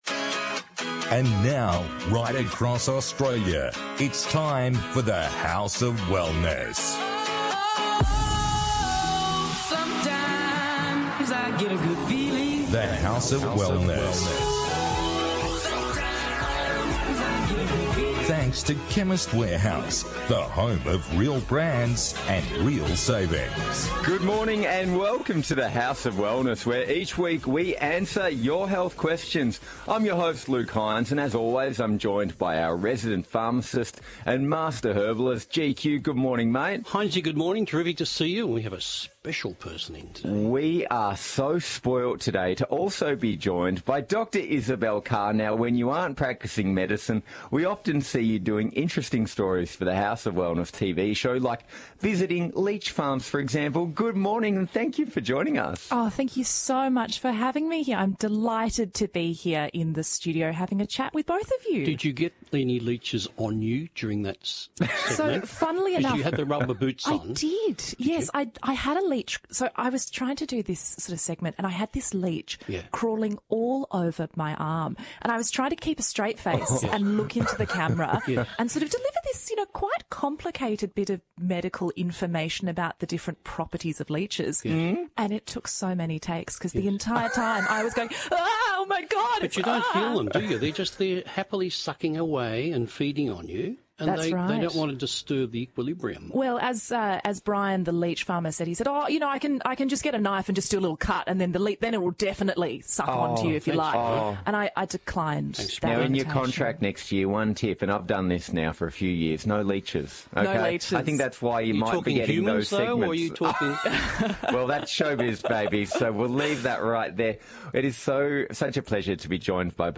Ovarian cancer, quitting smoking and when to change your toothbrush – tune into The House of Wellness radio show for all that and more.